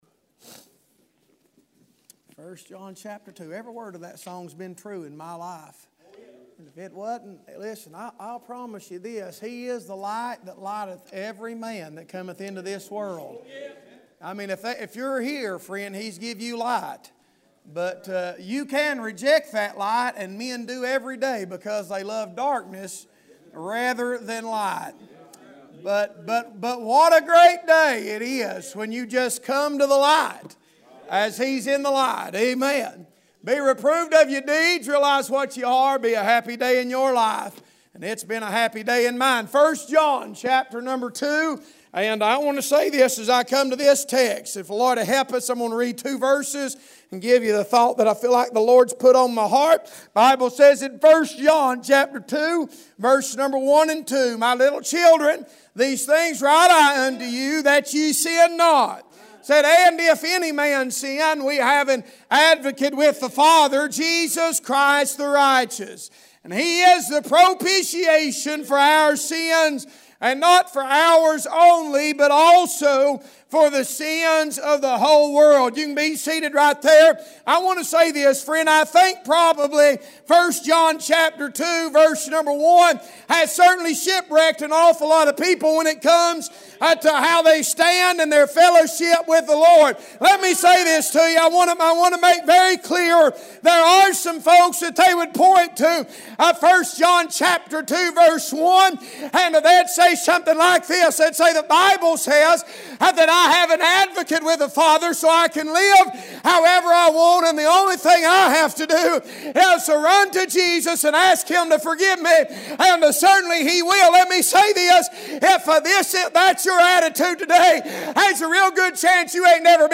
Passage: 1 John 2:1-2 Service Type: Wednesday Evening